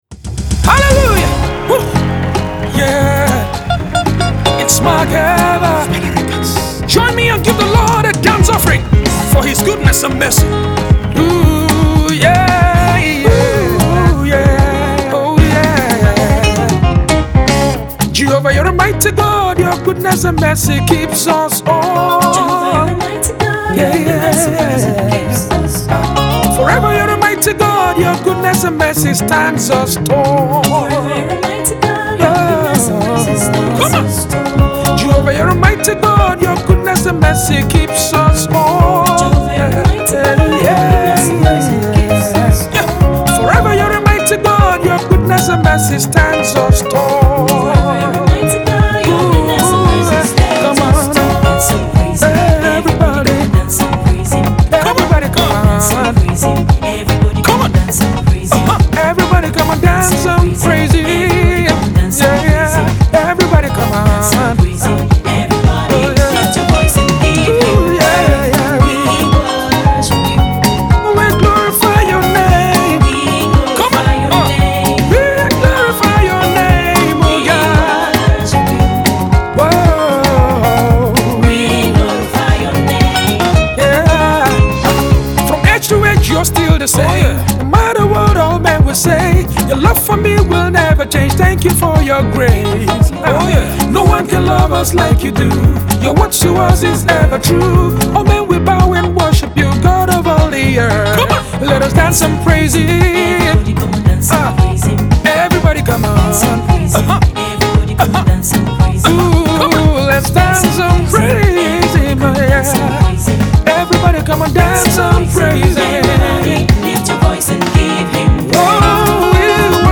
a Nigerian based gospel praise singer
a very groovy song and uptempo